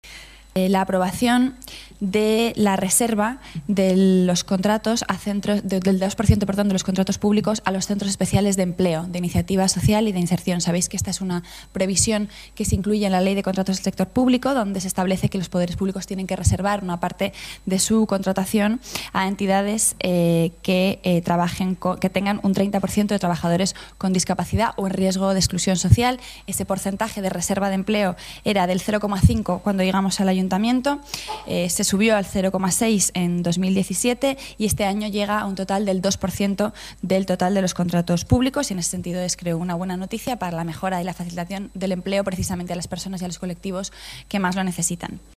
Nueva ventana:Rita Maestre, portavoz Gobierno municipal